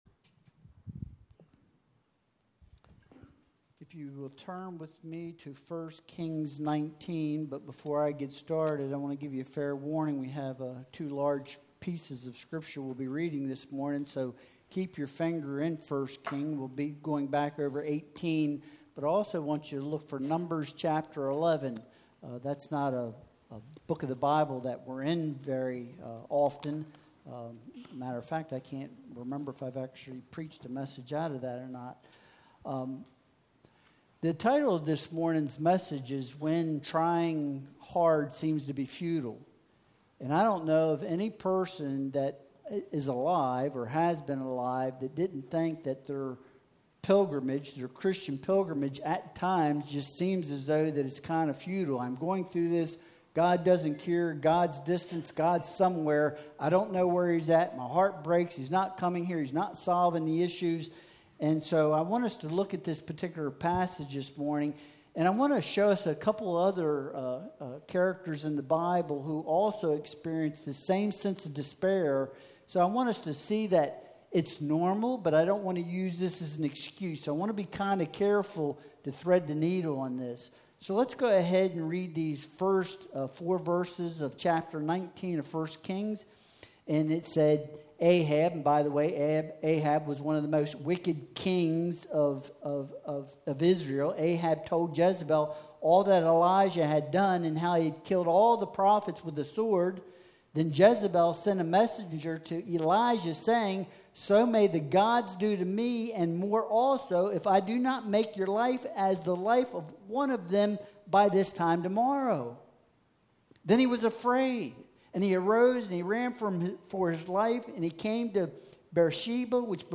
Passage: 1 Kings 19.1-4 Service Type: Sunday Worship Service Download Files Bulletin « The Power to Persevere Are You Beating Down or Building Up?